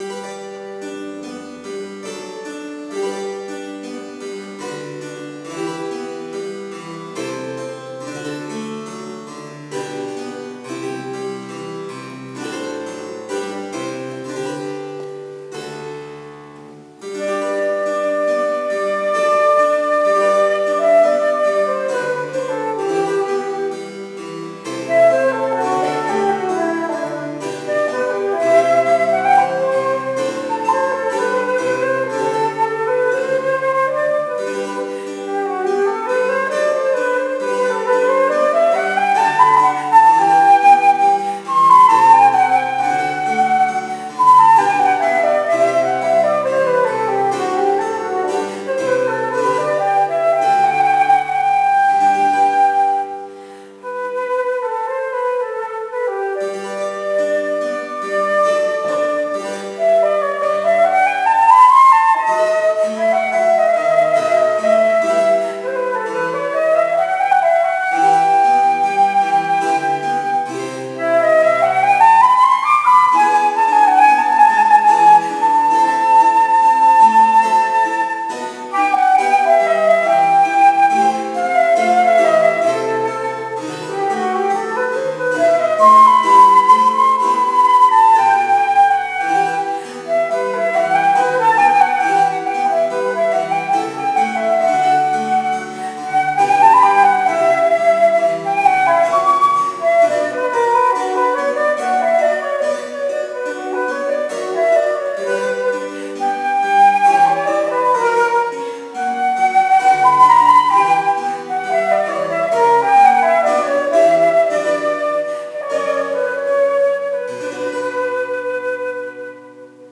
J. S.Bach - Sonáta e moll pro flétnu a cembalo,
flétna
cembalo